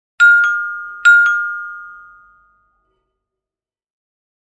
Ding-dong-doorbell-sound-effect-classic-two-chime-ringing-at-the-front-door.mp3